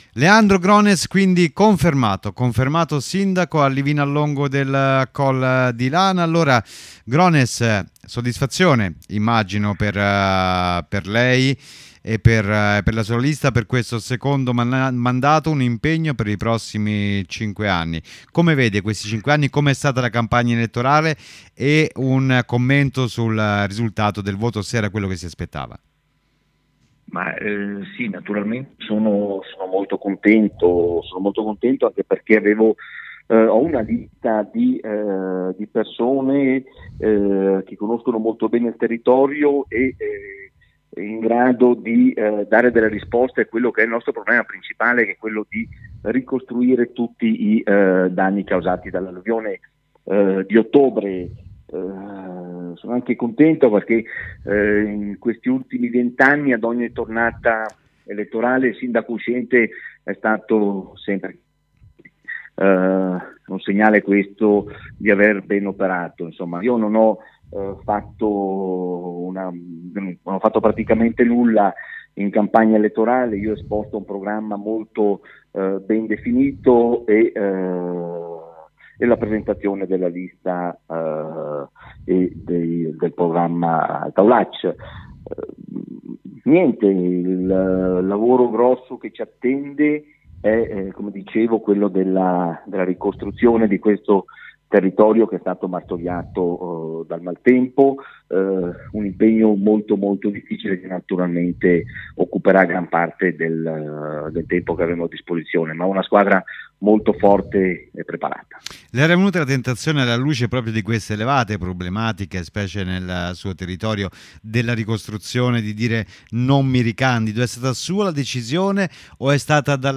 L’INTERVISTA AL SINDACO LEANDRO GRONES